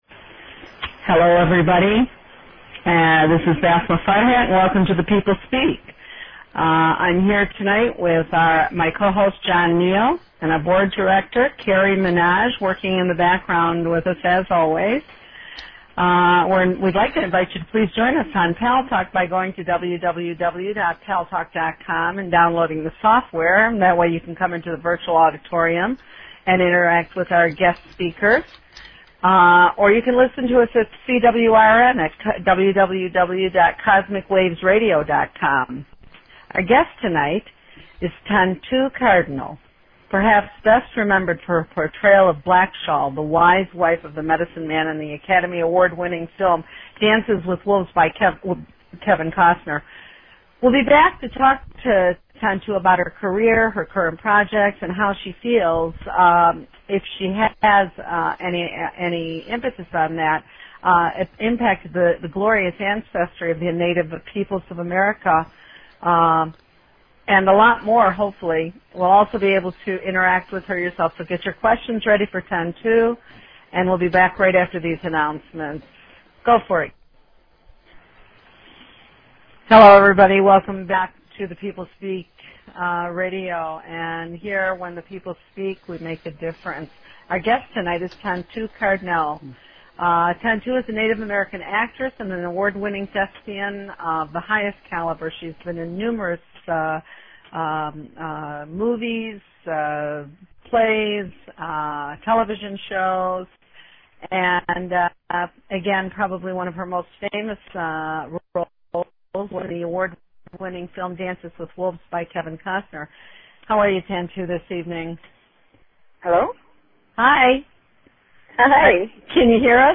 Guest, actress Tantoo Cardinal - the medicine man's wife in Dances with Wolves